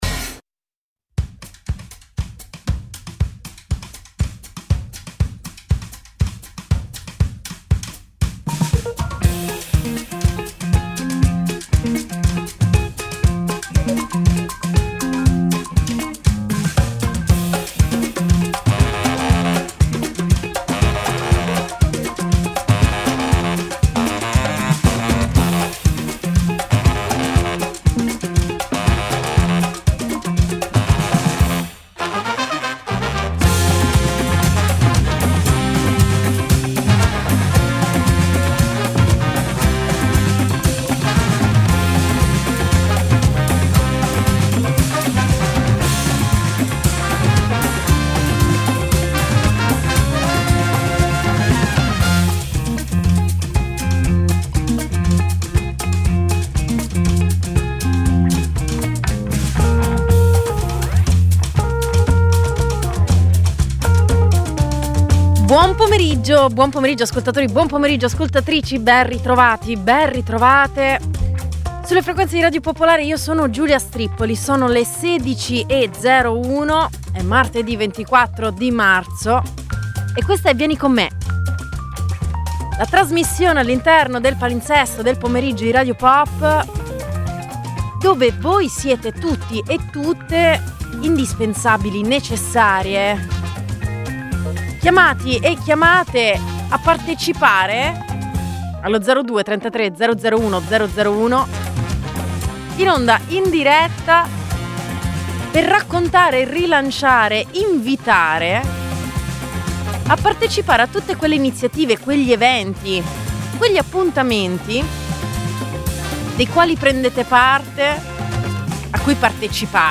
Uno spazio radiofonico per incontrarsi nella vita.